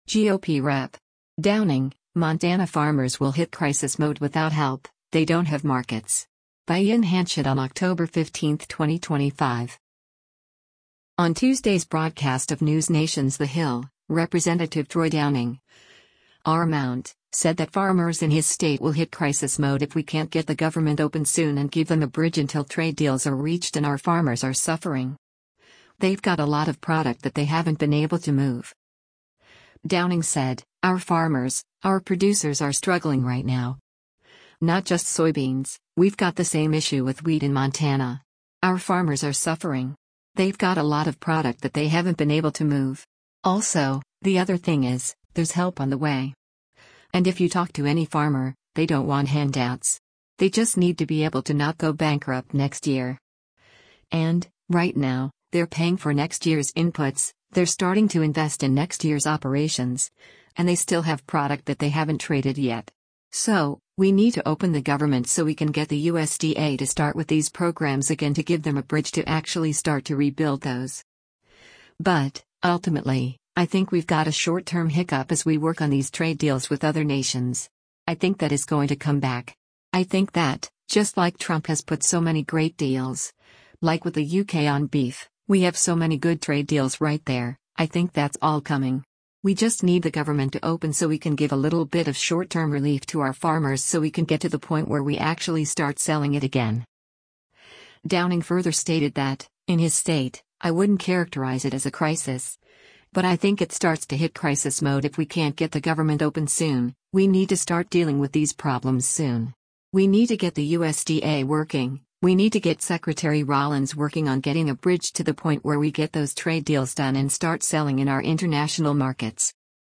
On Tuesday’s broadcast of NewsNation’s “The Hill,” Rep. Troy Downing (R-MT) said that farmers in his state will “hit crisis mode if we can’t get the government open soon” and give them a bridge until trade deals are reached and “Our farmers are suffering. They’ve got a lot of product that they haven’t been able to move.”